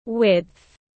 Chiều rộng tiếng anh gọi là width, phiên âm tiếng anh đọc là /wɪtθ/.
Width /wɪtθ/